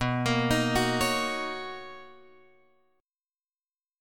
B Major 9th